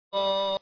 شعر و سبک زمینه سینه زنی برای شهادت امام محمد باقر(ع) -( یا باقر ِ آل عبا ، ای یادگار کربلا )